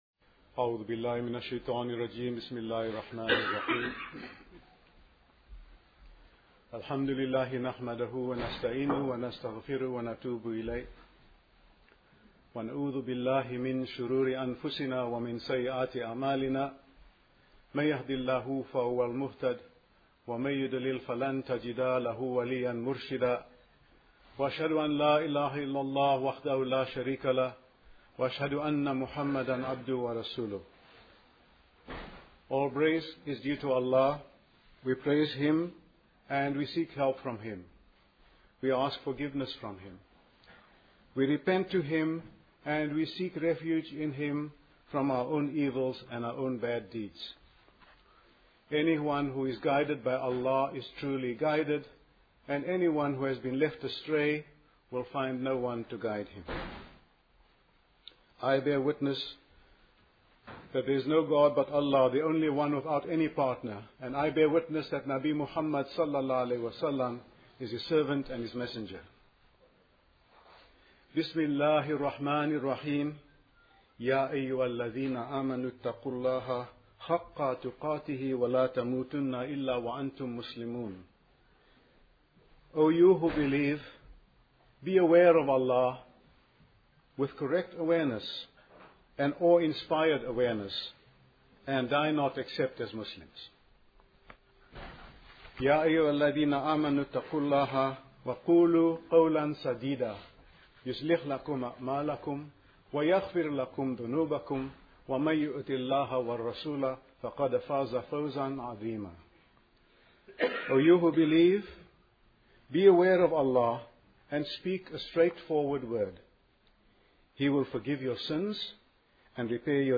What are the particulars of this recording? Royal Holloway University of London 5th February 2016